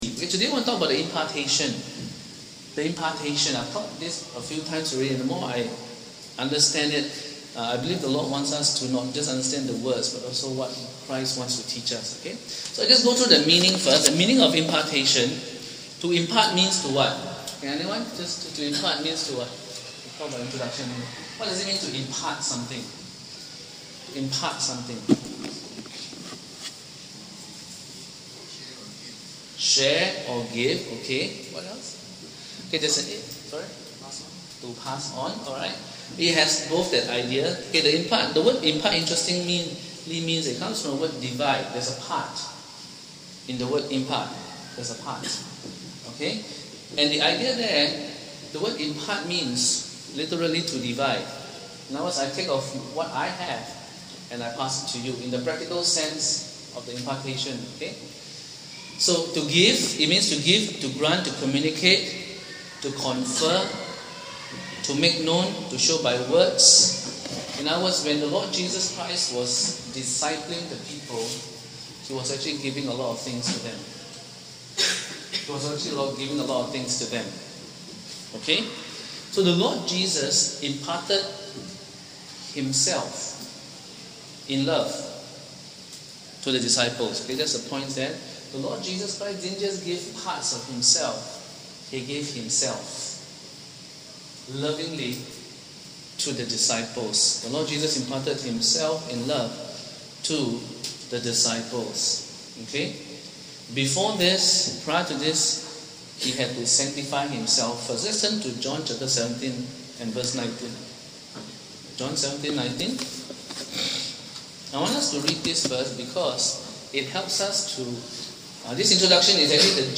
Due to technical difficulties, this week’s sermon has been recorded on a backup device and audio quality is reduced.